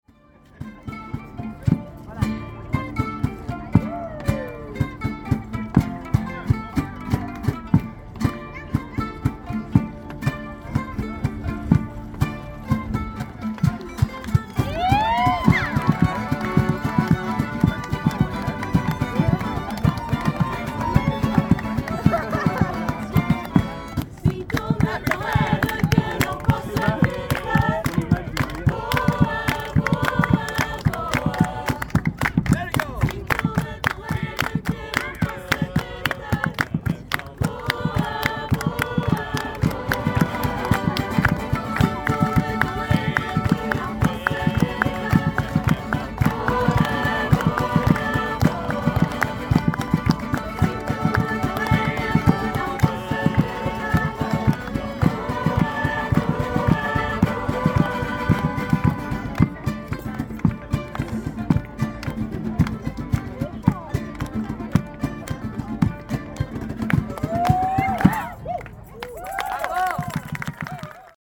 Артисты поют на улице под прекрасную музыку